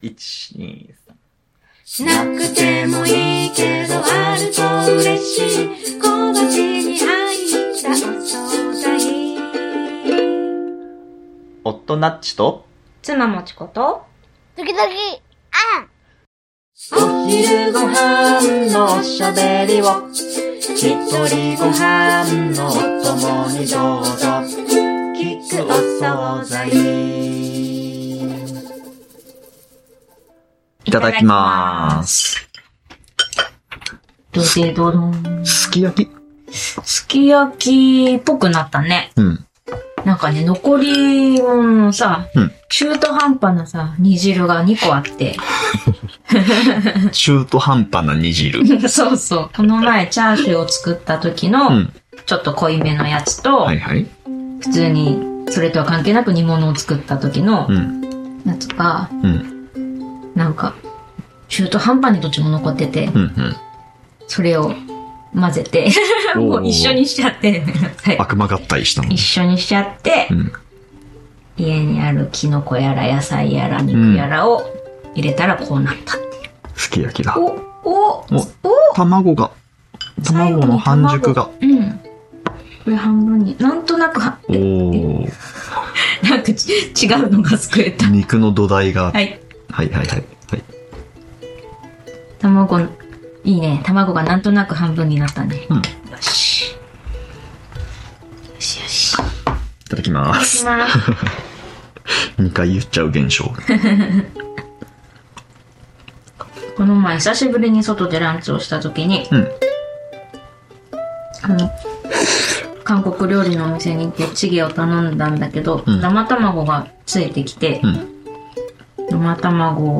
残り物リメイクの似非すき焼きをつつきながらの雑談です。初めてのひとり暮らしで調味料のサイズを買い間違えた記憶がある人は多いはず。